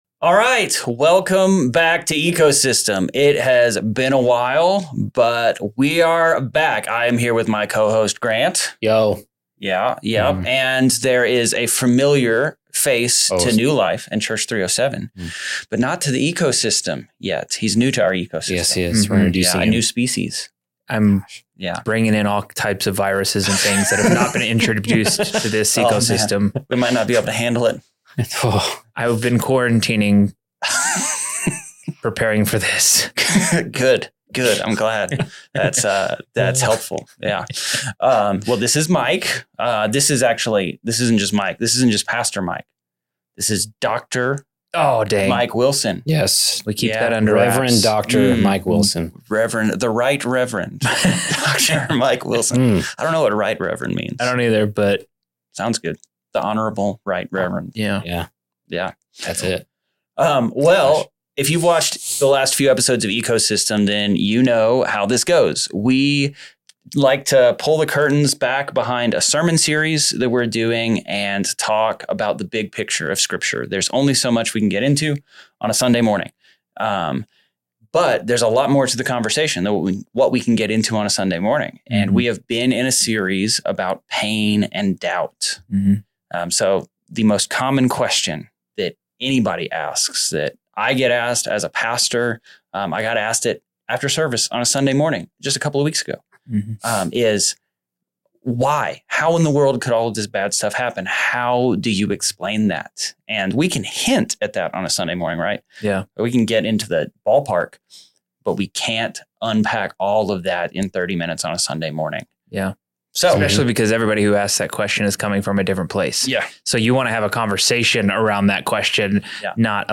This is Part 1 of a two-part conversation where we explore the role of suffering in faith, how struggles can shape our character, and what the Bible says about enduring hardship.